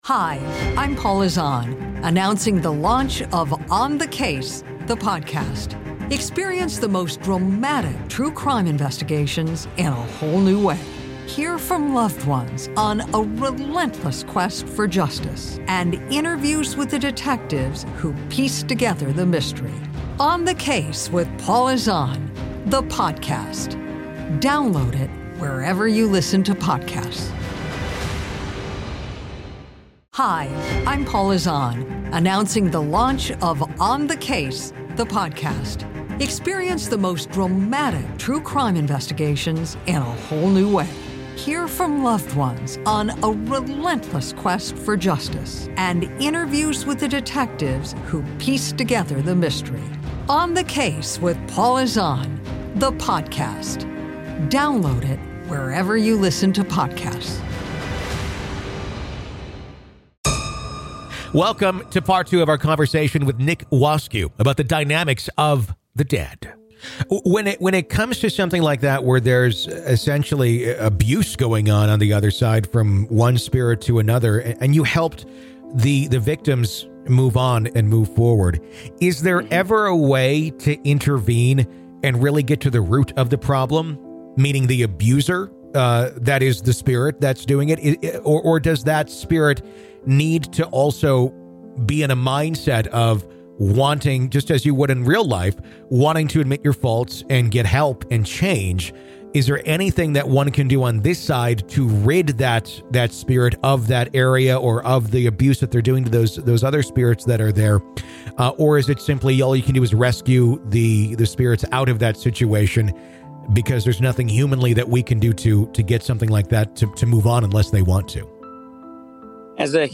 In Part One of this compelling Grave Talks interview